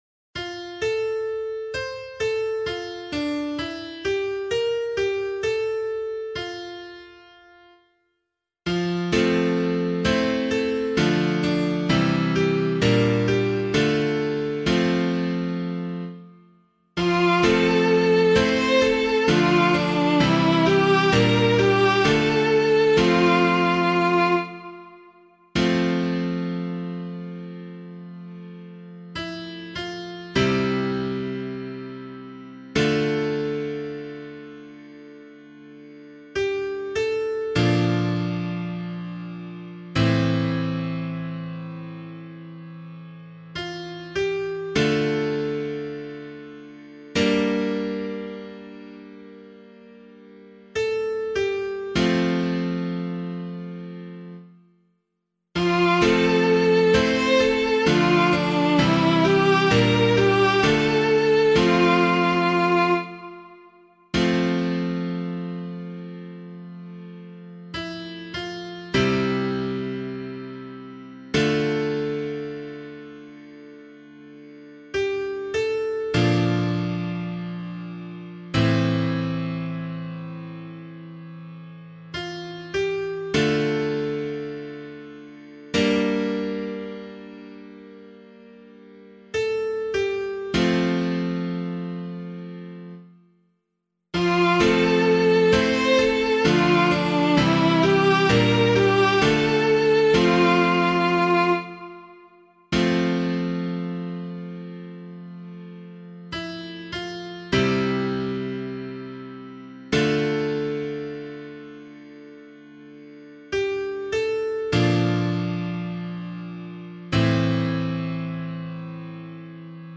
039 Ordinary Time 5 Psalm A [LiturgyShare 6 - Oz] - piano.mp3